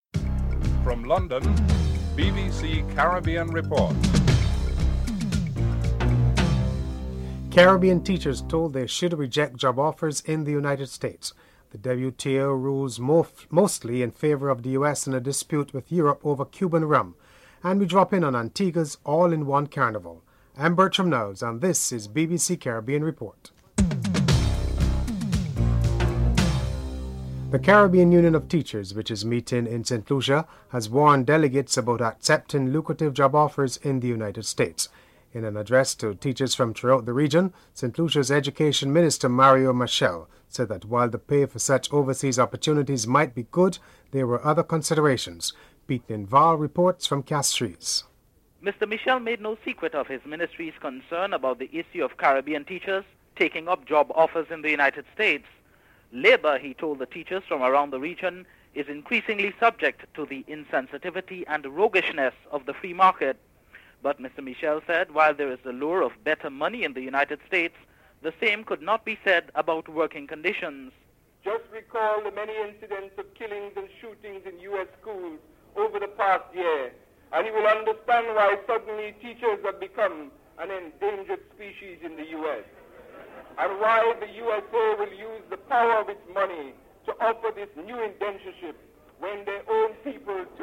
The British Broadcasting Corporation
1. Headlines (00:00-00:26)
New York City Council Member Jamaican born Una Clarke is interviewed (02:47-05:51)